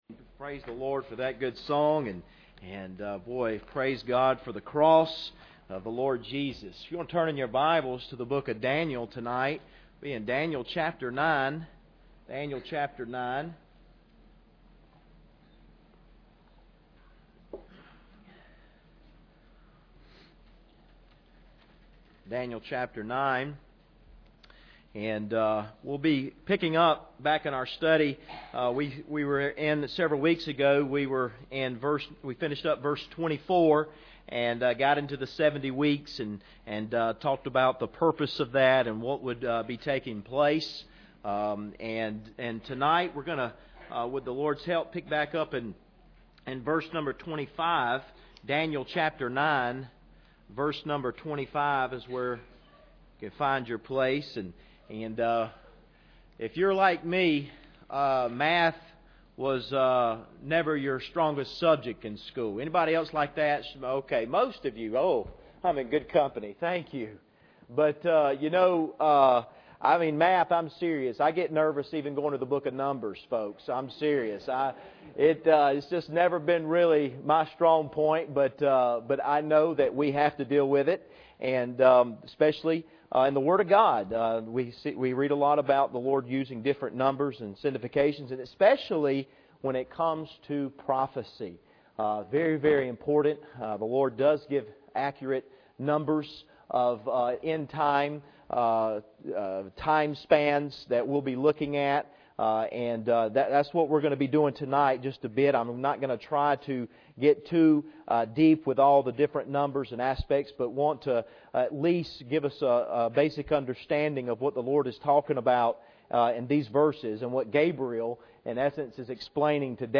Passage: Daniel 9:25 Service Type: Sunday Evening